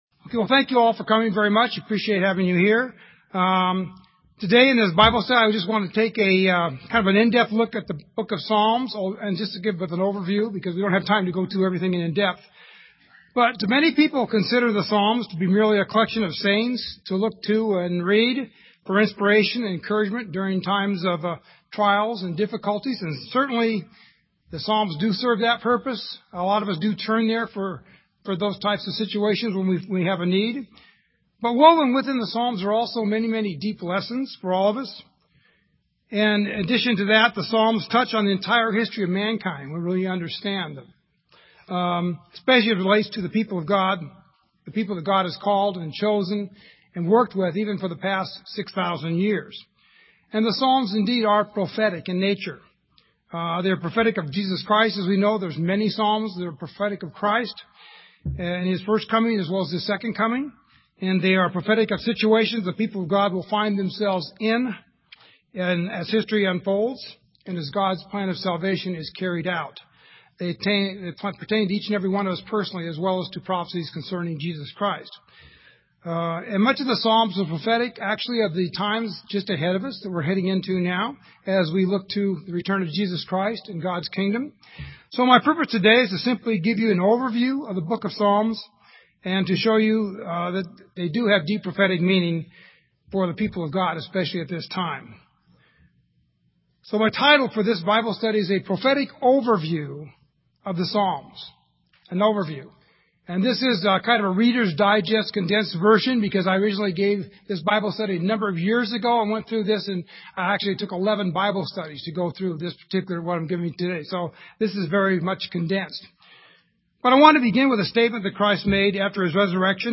This sermon was given at the Bend, Oregon 2012 Feast site.